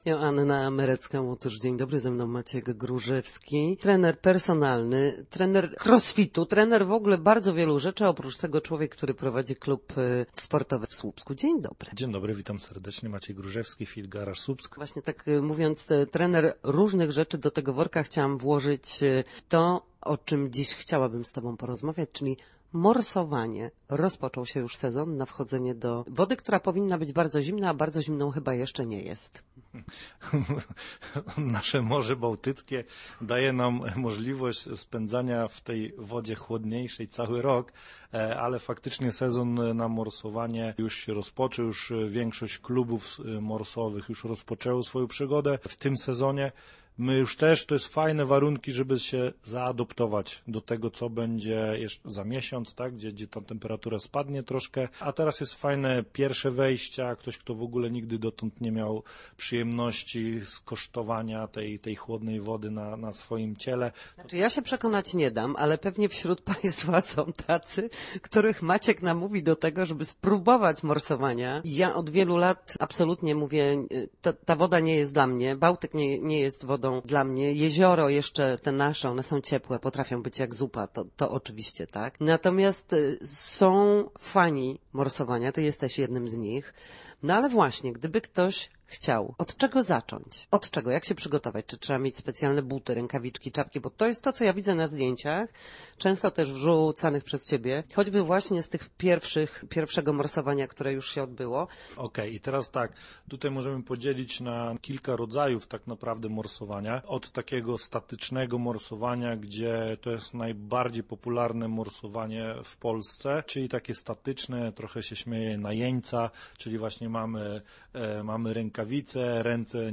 Na naszej antenie mówił o tym, jak rozpocząć przygodę z morsowaniem i jak sprawić, aby kontakt z zimną wodą wszedł nam w nawyk.